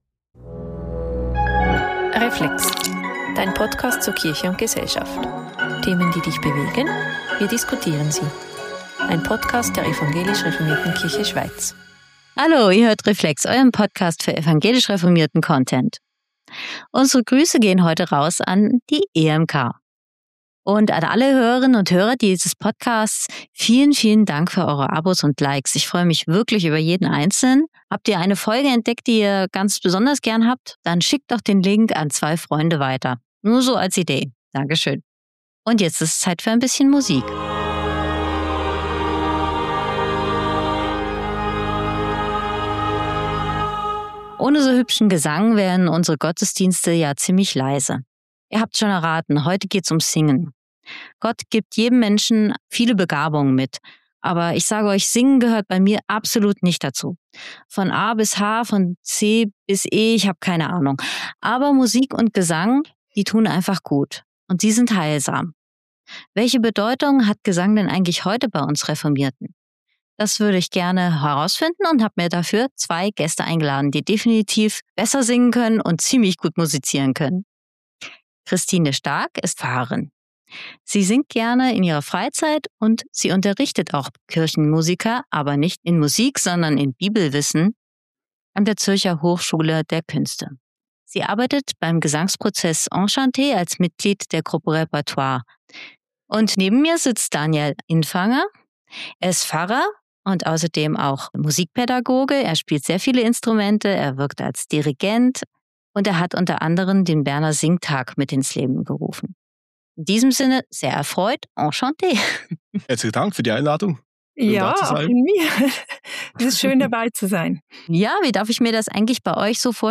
Orgel und Gesang